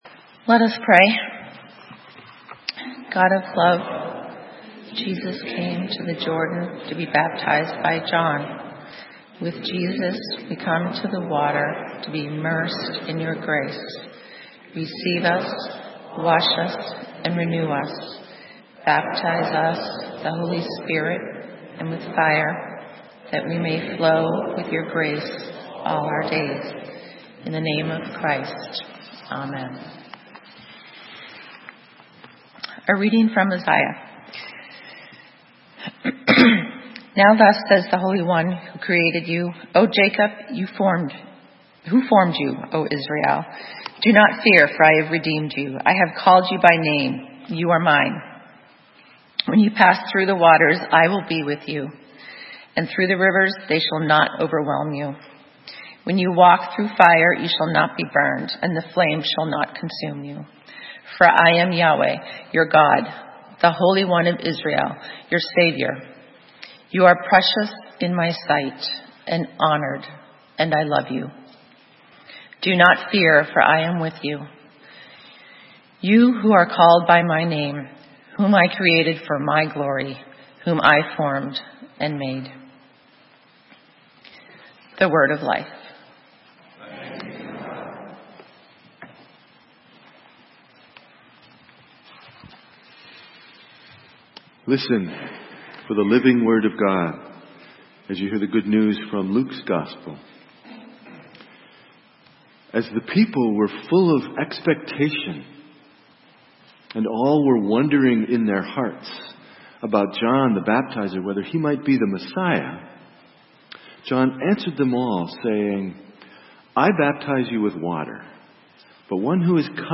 Sermon:The nerve! - St. Matthew's UMC